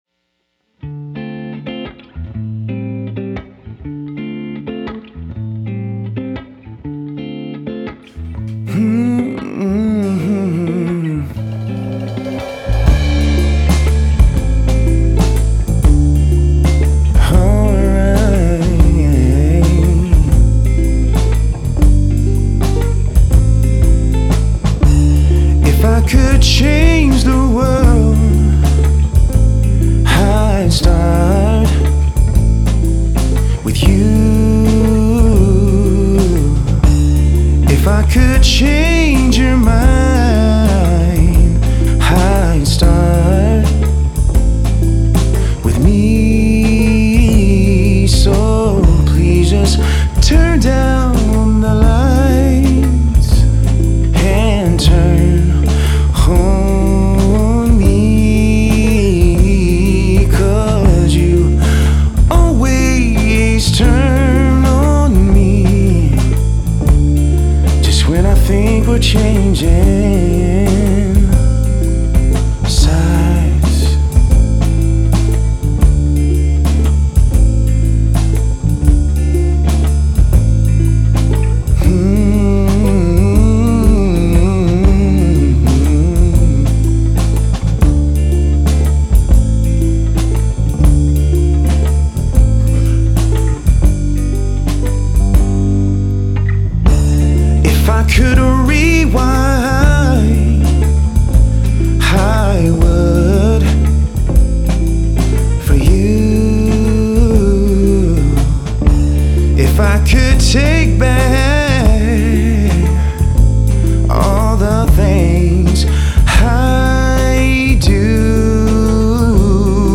Ist mir klanglich zu hart für diese Nummer.
Die Ukulele spielt irgendwie weiter hinten, zu weit hinten. Die Overheads sind mir auch einen ticken zu laut, machen es schnell nervös statt gemütlich.